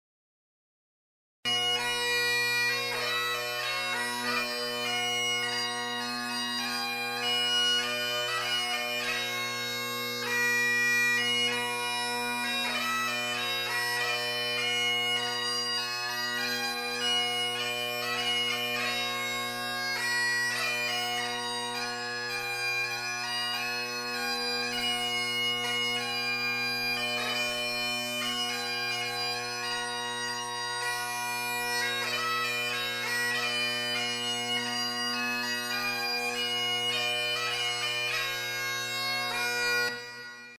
Scottish and Irish Bagpipe Music
The Minstrel Boy – 4/4 Irish March